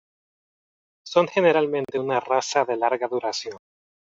du‧ra‧ción
/duɾaˈθjon/